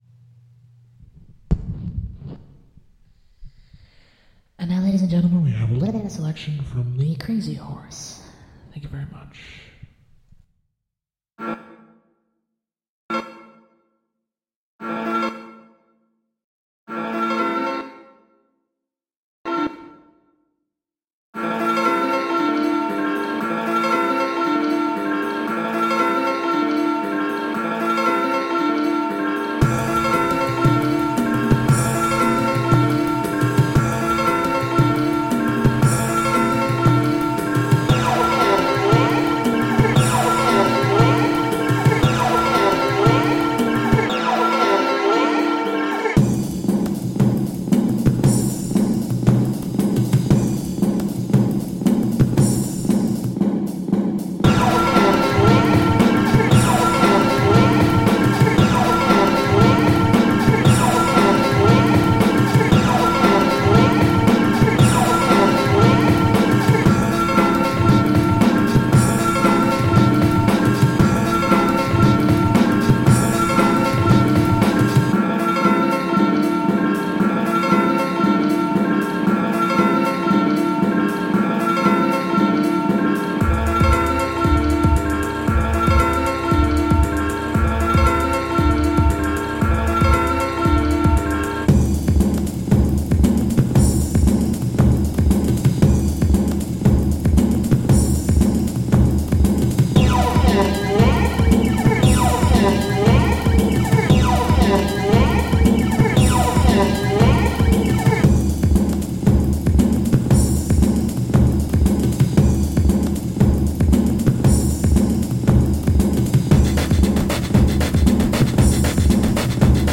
Clean, elemental electronic.
Tagged as: Electronica, Pop, Experimental Electronic